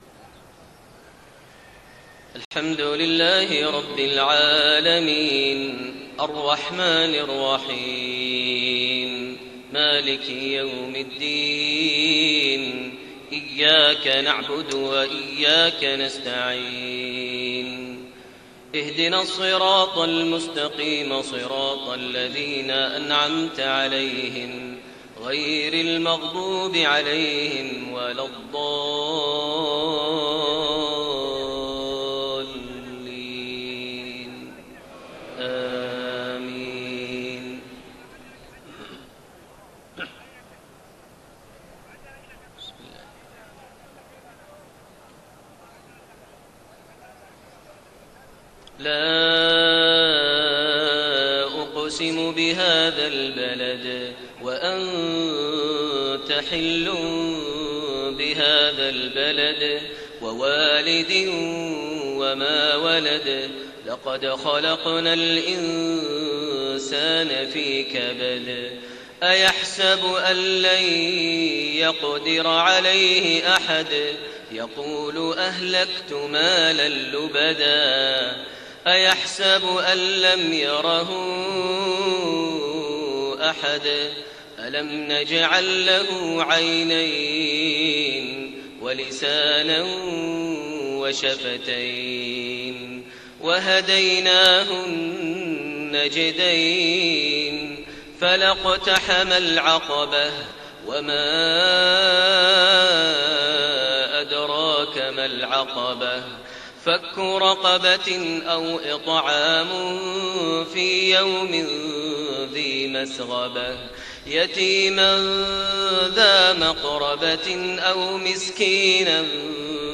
صلاة المغرب 6-3-1432 سورتي البلد و الهمزة > 1432 هـ > الفروض - تلاوات ماهر المعيقلي